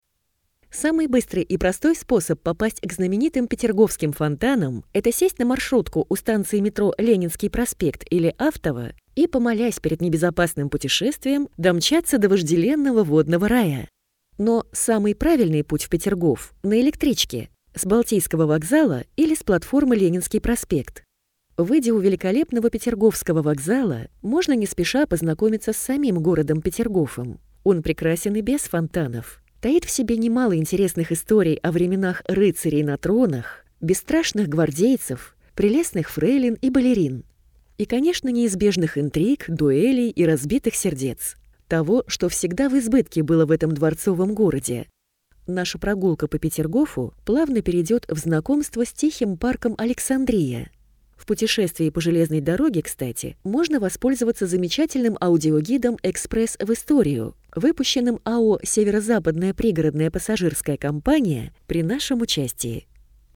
Аудиокнига Петергоф: от вокзала в парк Александрия. Аудиогид | Библиотека аудиокниг